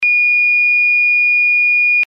ZUMBADOR - SONIDO CONTINUO
Zumbador de fijación mural
Sonido continuo
90dB